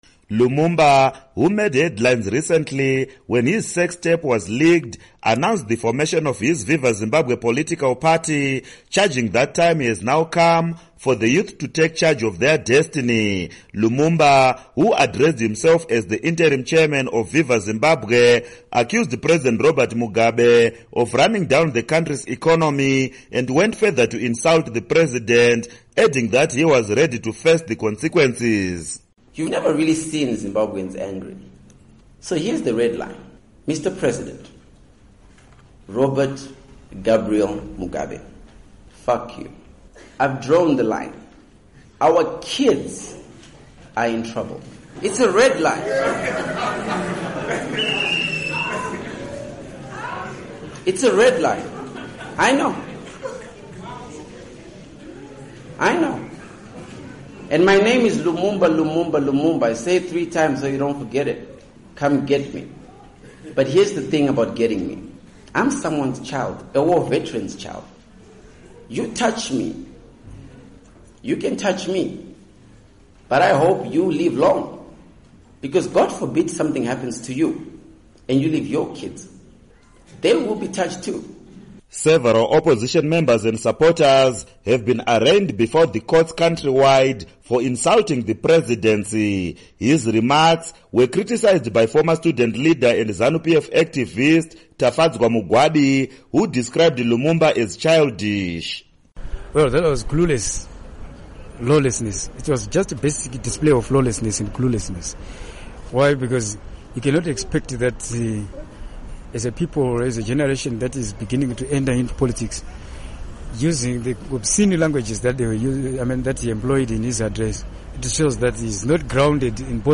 Report On Lumumba